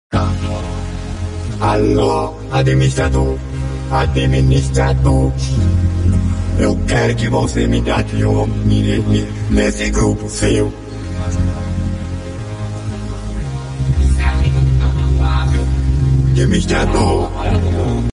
Mp3 Sound Effect